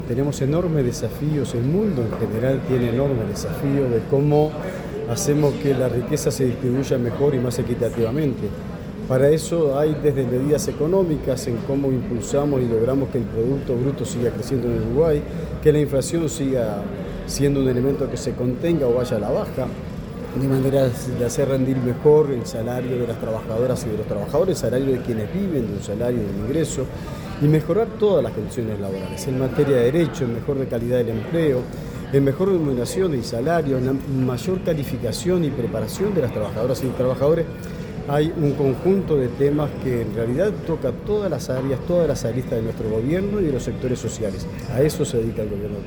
Declaraciones del ministro de Trabajo y Seguridad Social, Juan Castillo